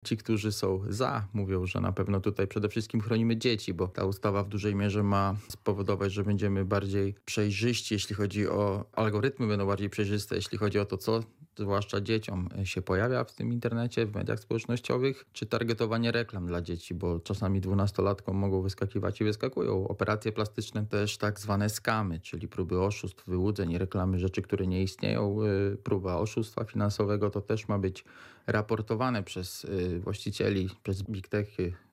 gość RL